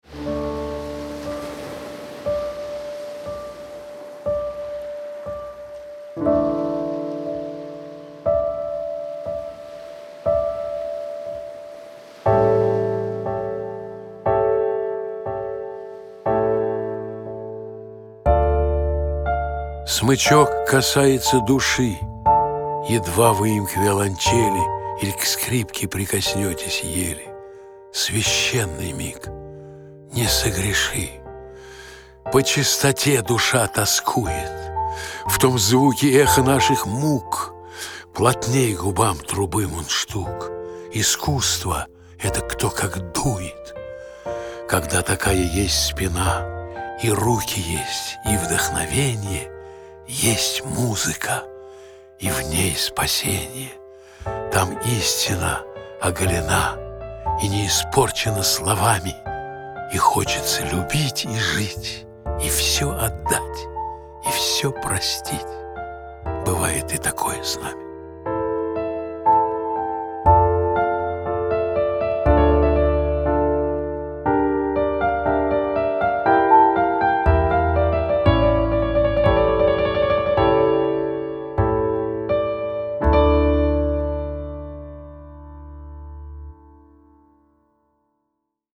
Избранное Автор Валентин Гафт Читает аудиокнигу Валентин Гафт.